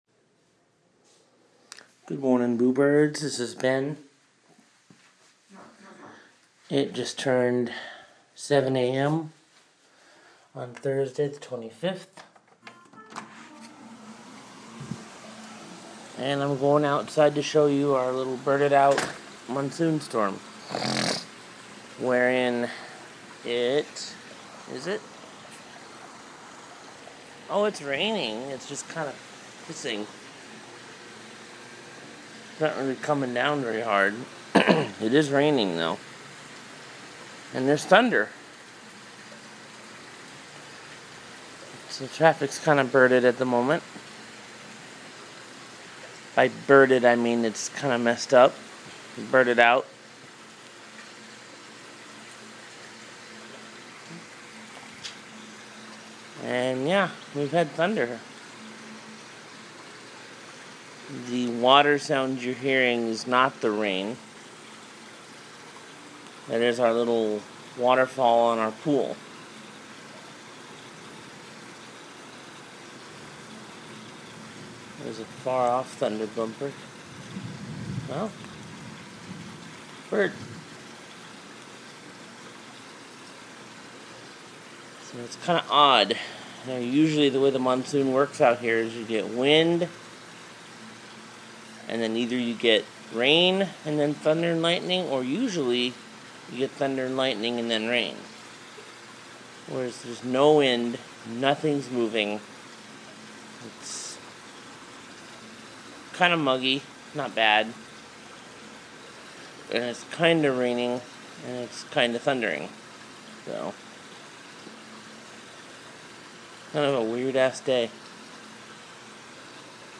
Our birded out little monsoon storm